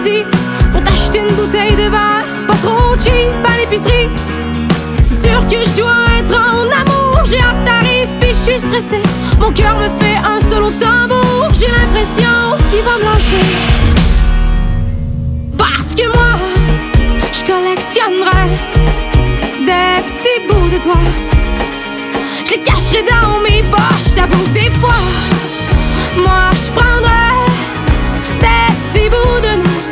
• Musique francophone